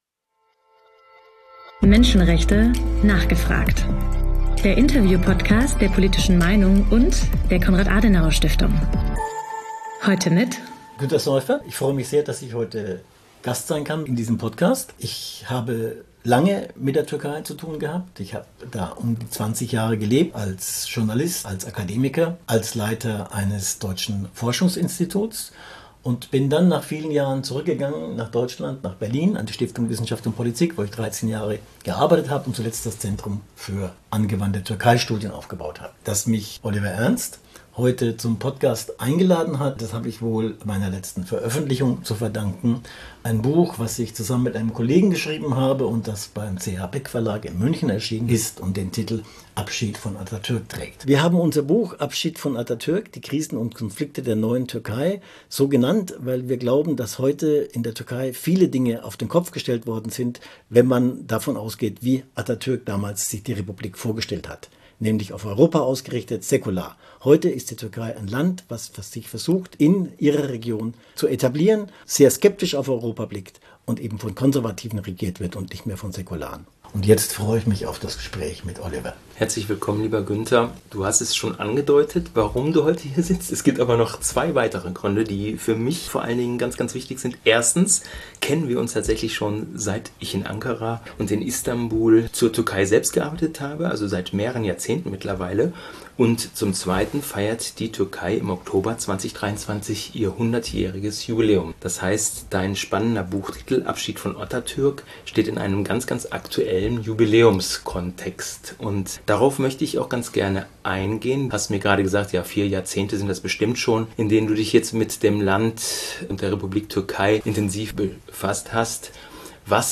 Menschenrechte: nachgefragt! - Der Interview-Podcast rund ums Thema Menschenrechte